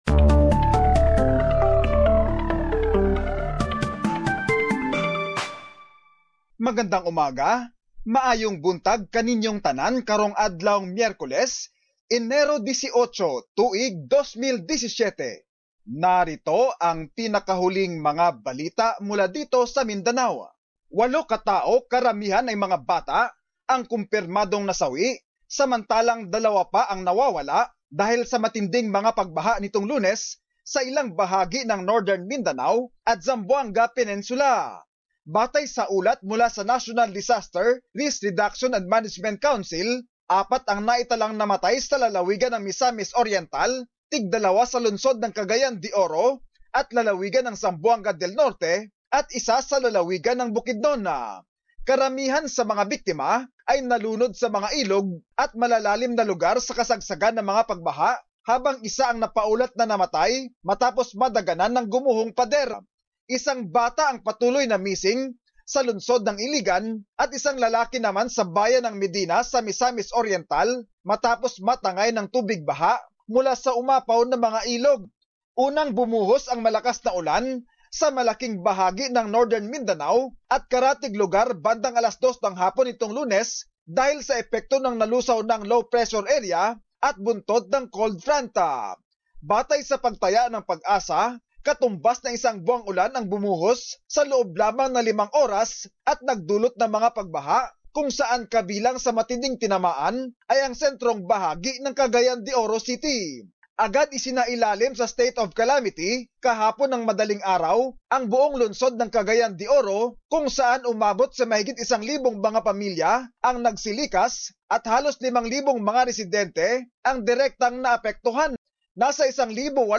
Mindanao News.